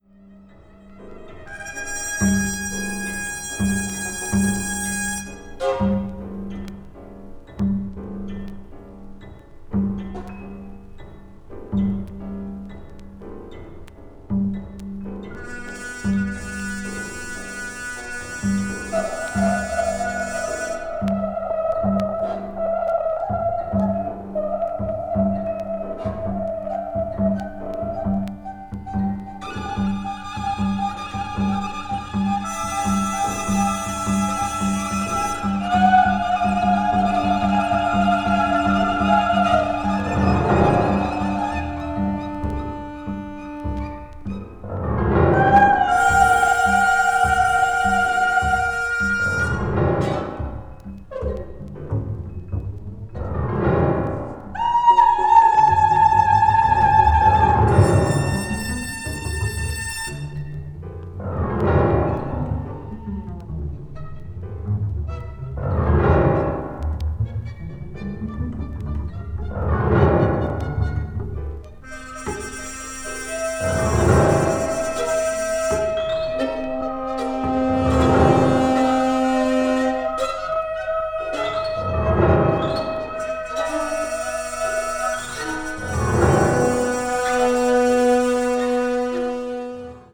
media : VG+/VG+(薄い擦れによるわずかなチリノイズが入る箇所あり,軽いプチノイズが入る箇所あり)